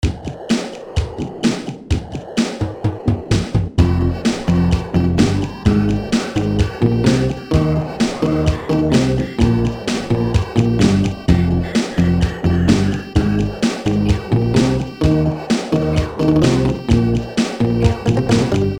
Note: this is not finished.